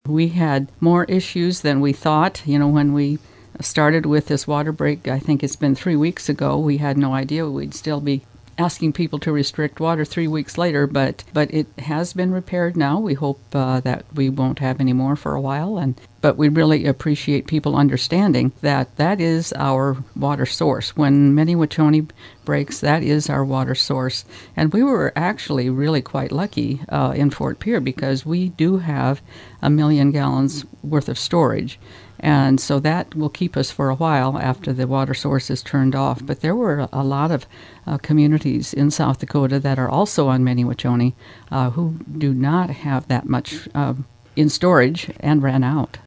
Fort Pierre mayor Gloria Hanson says the water line break turned out to be more complicated than was originally thought.